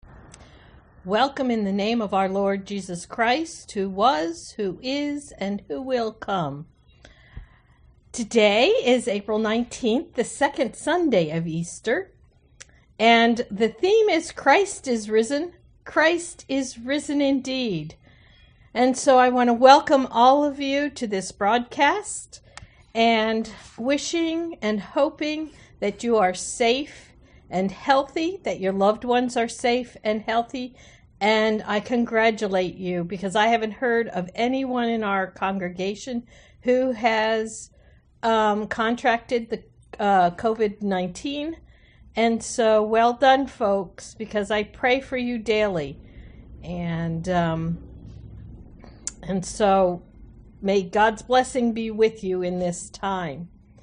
Prelude: Lift High the Cross